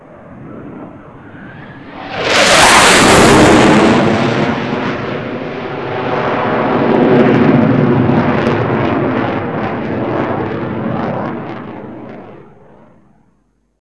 flyby.wav